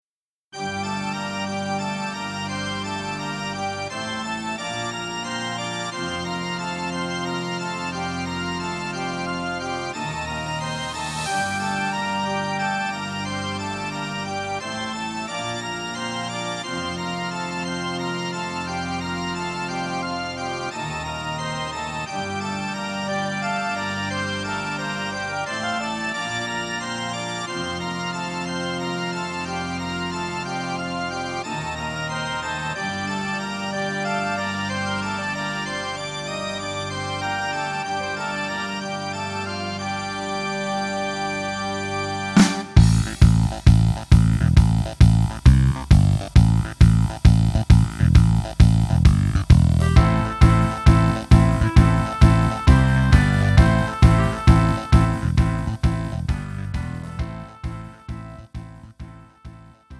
음정 -1키 3:25
장르 가요 구분 Pro MR